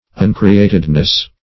Uncreatedness \Un`cre*at"ed*ness\, n. The quality or state of being uncreated.
uncreatedness.mp3